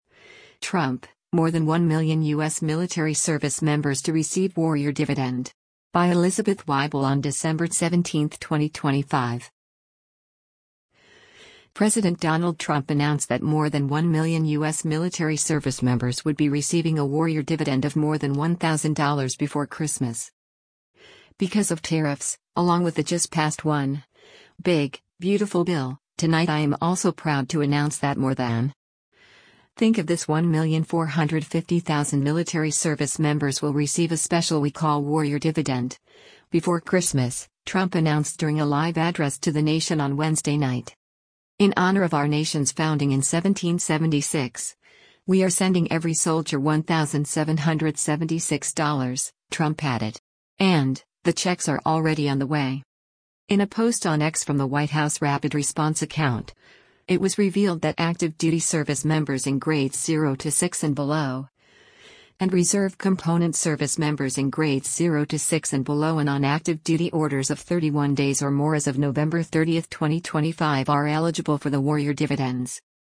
“Because of tariffs, along with the just passed One, Big, Beautiful Bill — tonight I am also proud to announce that more than……. think of this 1,450,000 military service members will receive a special we call warrior dividend, before Christmas,” Trump announced during a live address to the nation on Wednesday night.